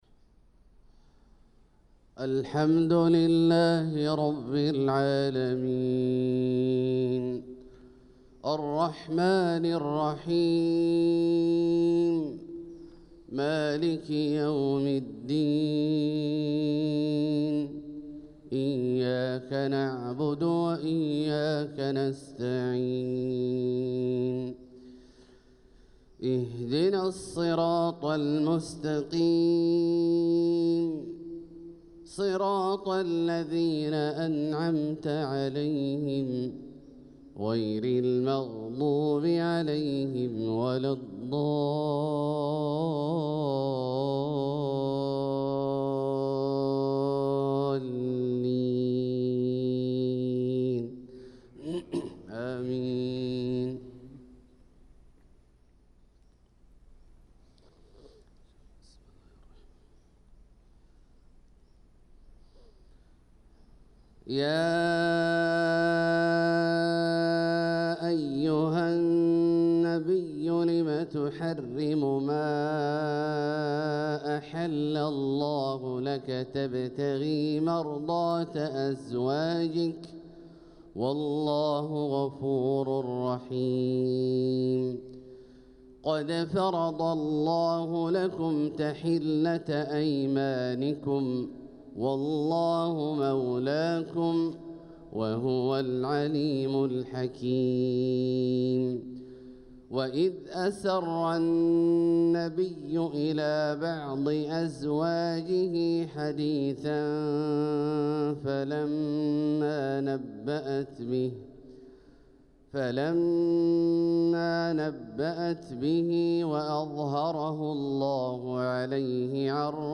صلاة الفجر للقارئ عبدالله الجهني 7 ربيع الأول 1446 هـ
تِلَاوَات الْحَرَمَيْن .